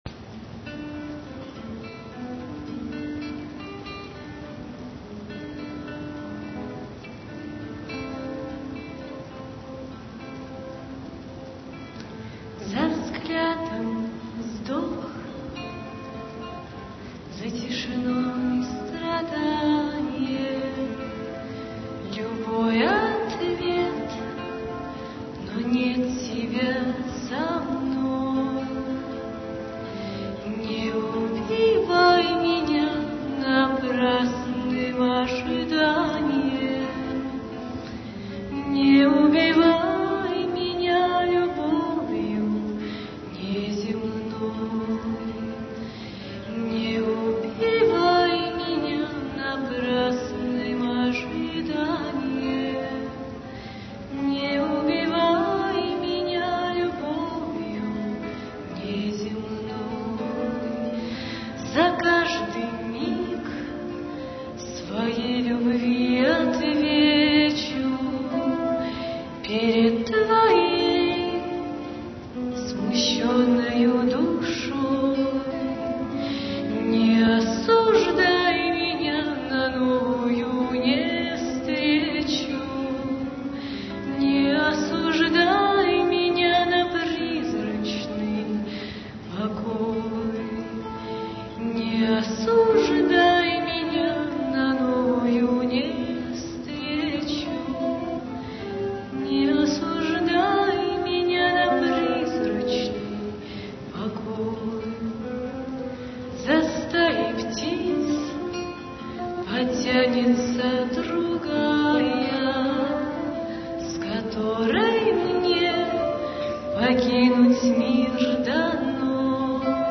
соло-гитара